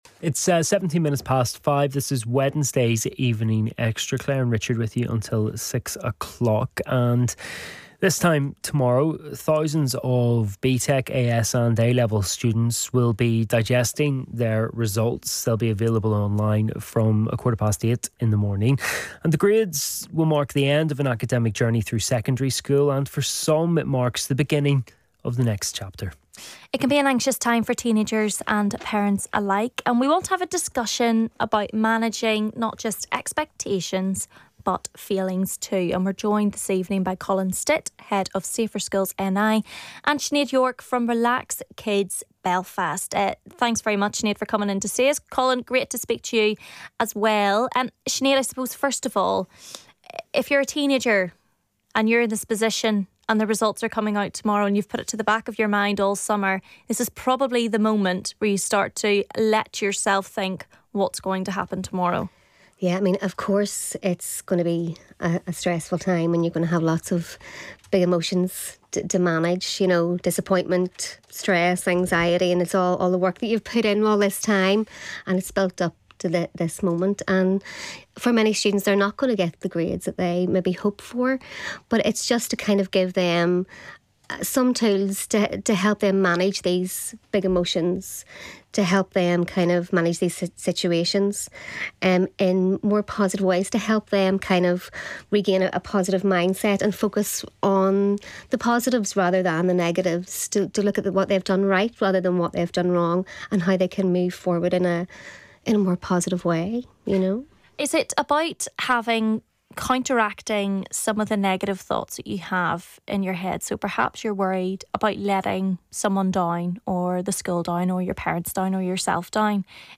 bbc-radio-ulster-evening-extra-interview-exam-results-day-full-segment-.mp3